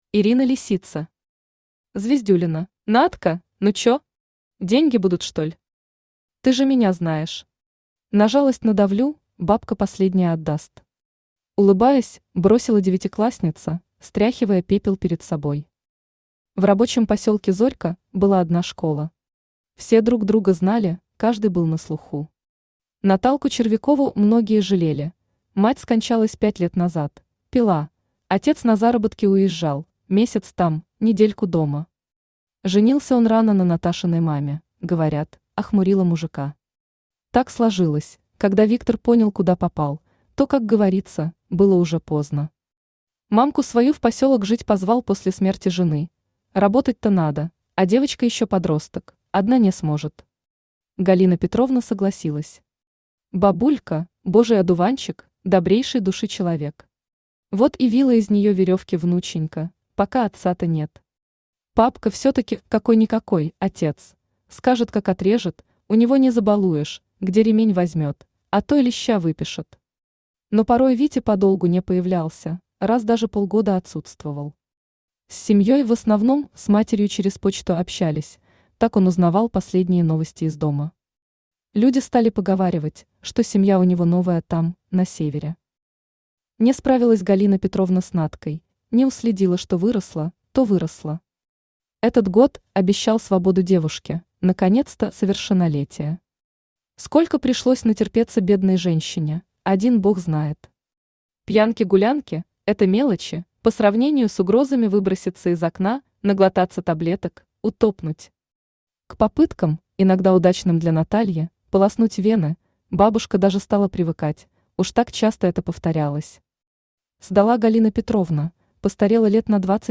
Аудиокнига Звездюлина | Библиотека аудиокниг
Aудиокнига Звездюлина Автор Ирина Лисица Читает аудиокнигу Авточтец ЛитРес.